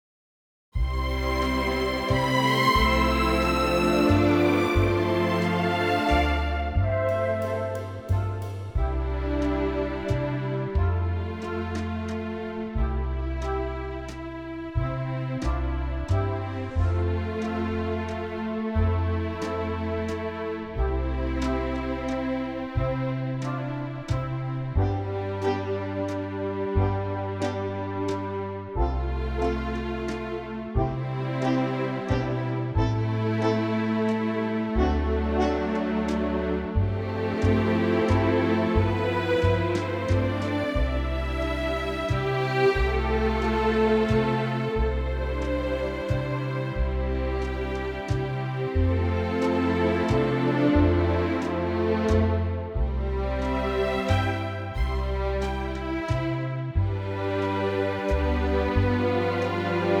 key - C - vocal range - C to E
Lovely orchestral arrangement, in 3/4 modern waltz tempo